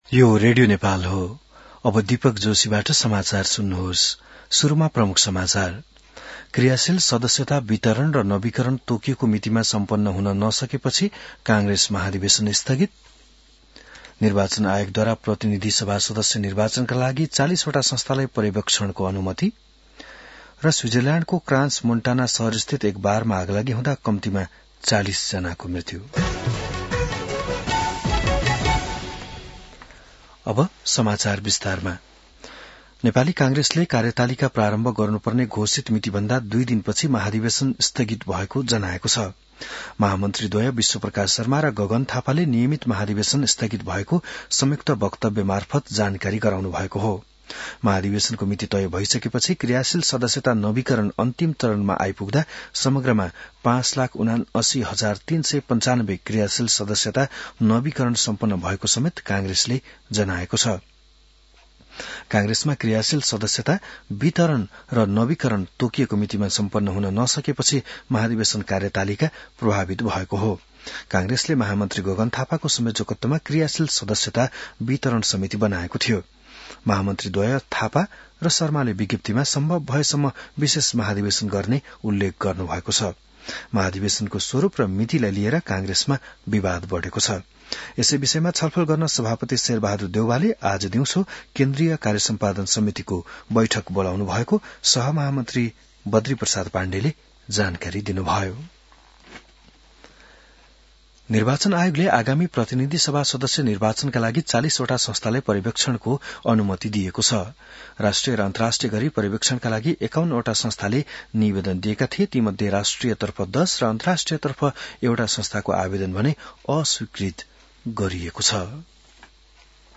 बिहान ९ बजेको नेपाली समाचार : १८ पुष , २०८२